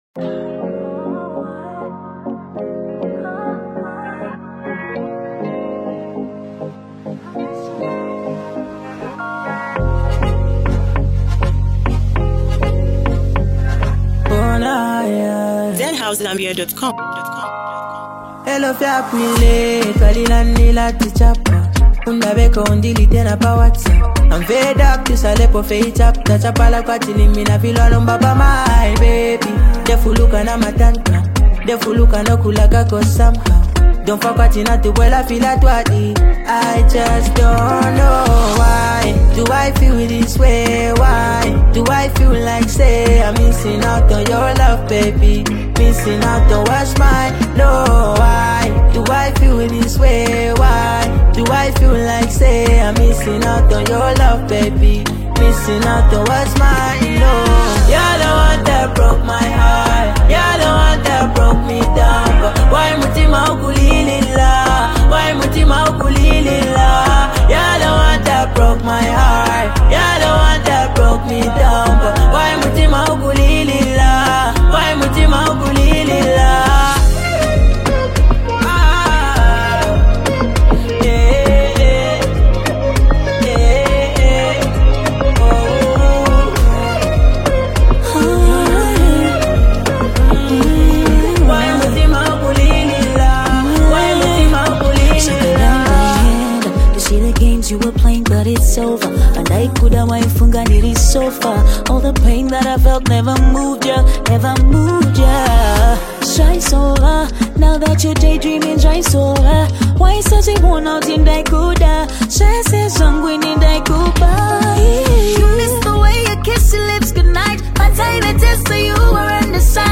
soulful music